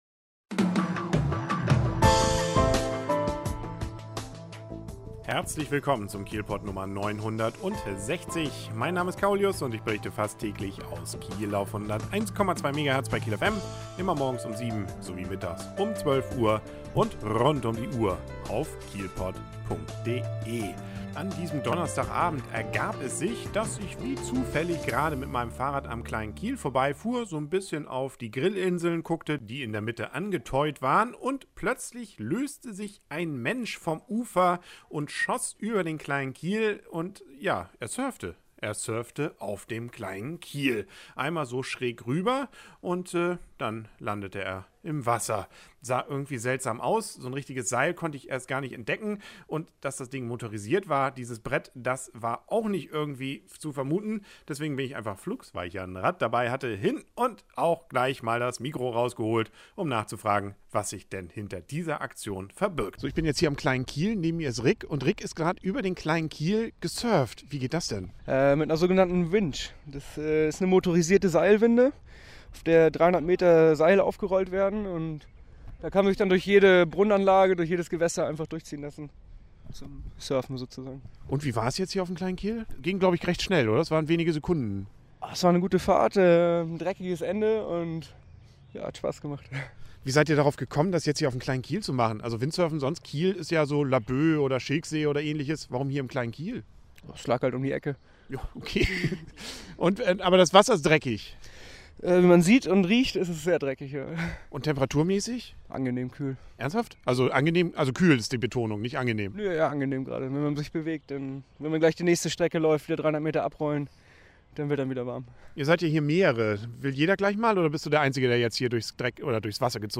Auf dem Kleinen Kiel kann man jetzt nicht nur grillen, sondern auch surfen. Wie das sein kann, dazu ein Interview in diesem KielPod.